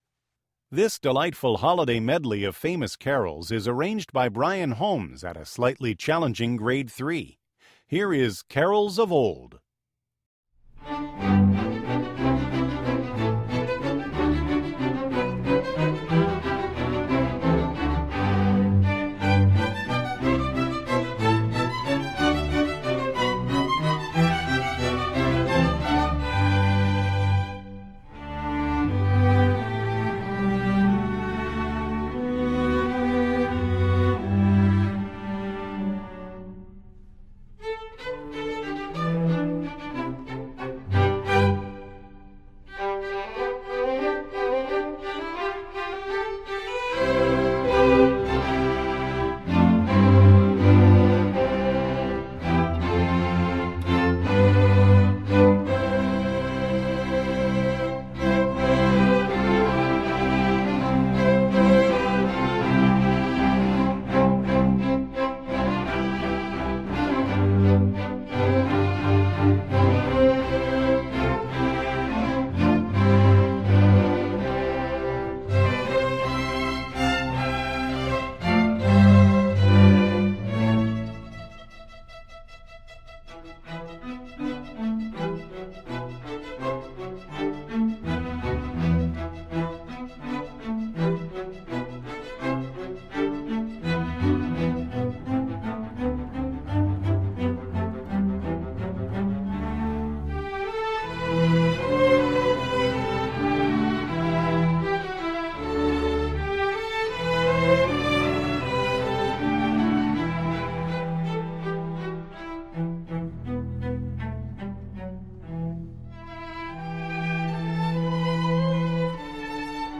Composer: English Carols
Voicing: String Orchestra